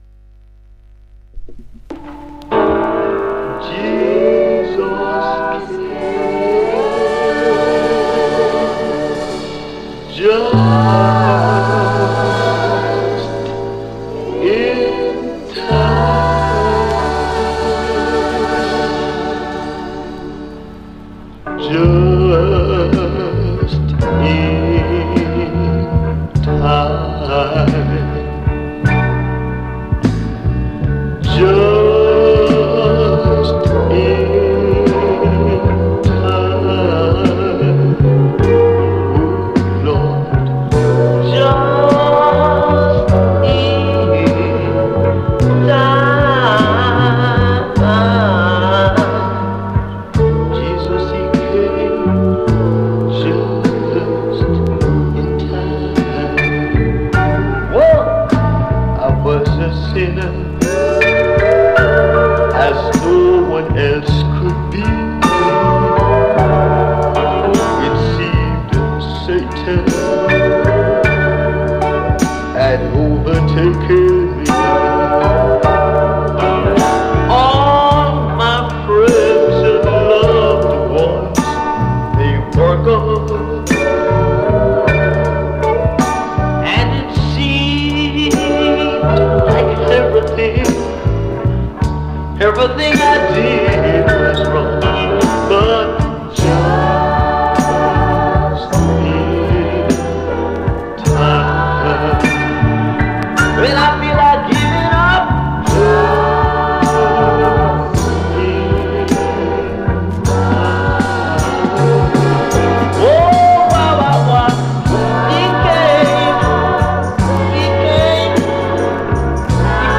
Every week,one hour with reggae music!Only vinyl!Big Up all listeners